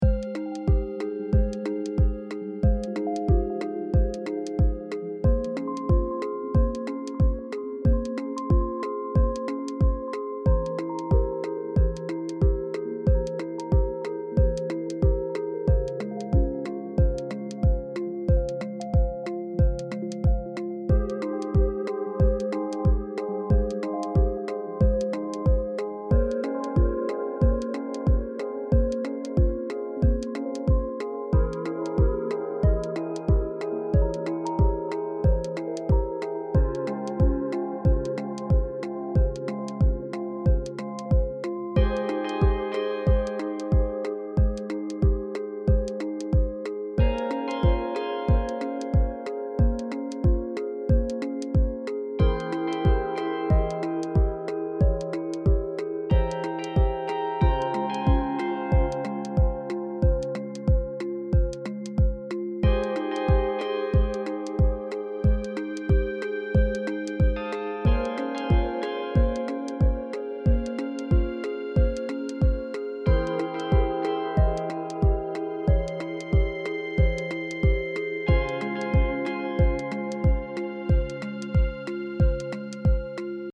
Another emotional-type piece